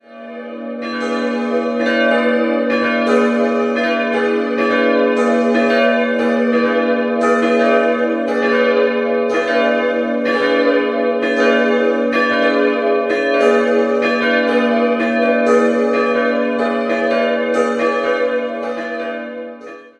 Der Chor verfügt über ein spätgotisches Netzrippengewölbe, die drei Altäre sind neugotische Arbeiten. 3-stimmiges Geläut: gis'-h'-cis'' Die Glocken wurden im Jahr 1923 vom Bochumer Verein für Gussstahlfabrikation gegossen.